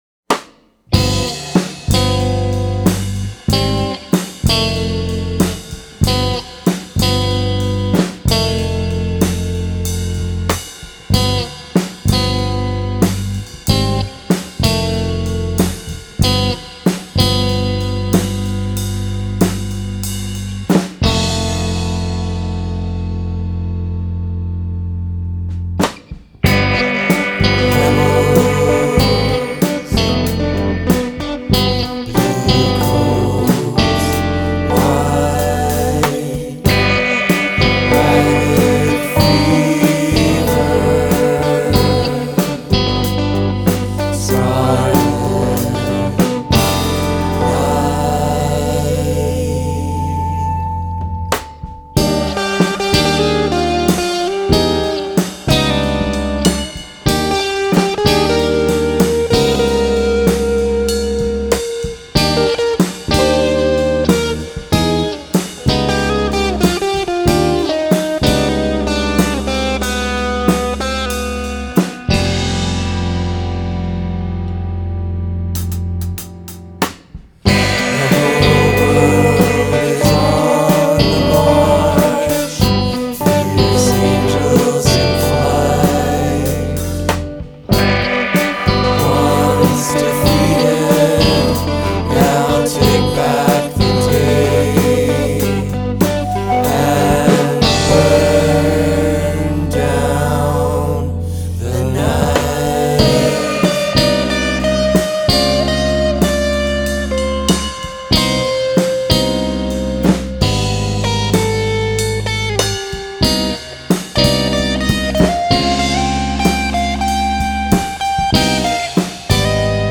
Recorded in Winnipeg and Toronto in Winter, 2021
tenor sax
guitar
guitars/keyboard/vocals
bass/vocals
drums/percussion
guest vocalist